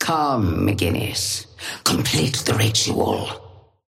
Sapphire Flame voice line - Come, McGinnis. Complete the ritual.
Patron_female_ally_forge_start_02.mp3